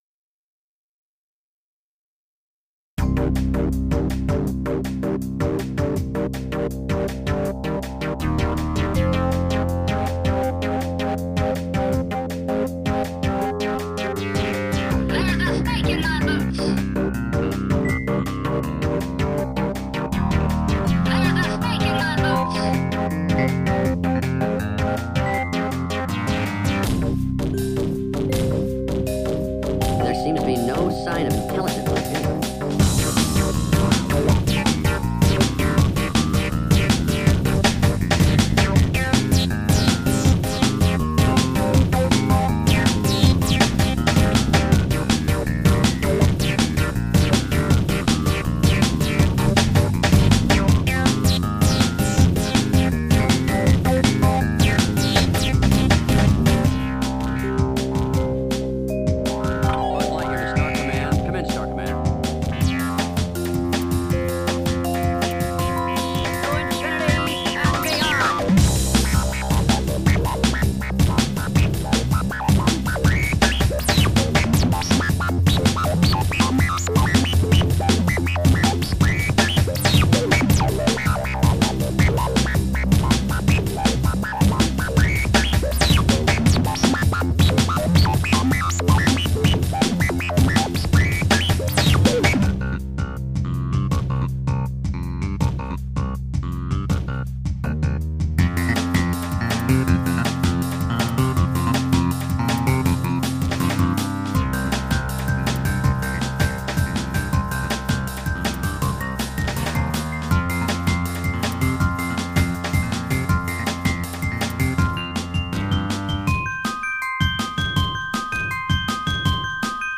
Vos Compos Electronique
m^m pb de dynamique ca pete pas assez pourtant tes drums sont sympa faut revoir ton mix